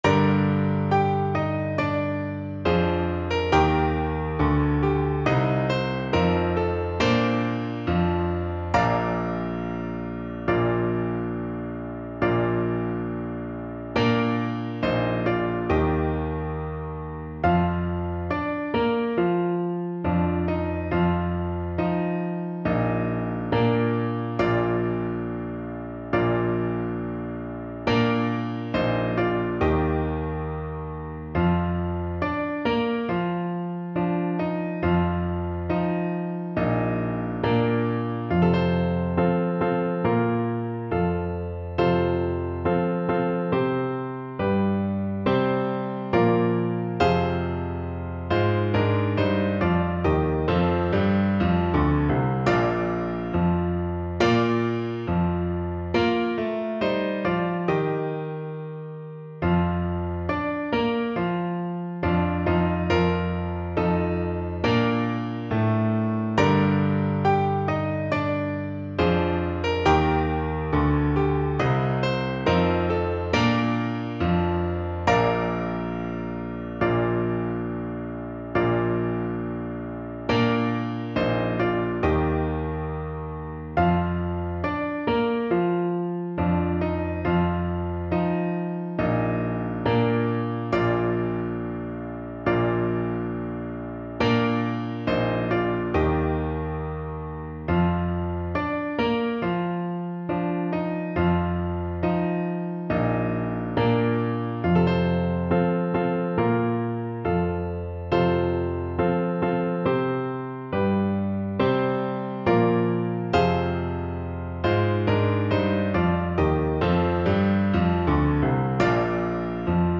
Piano Playalong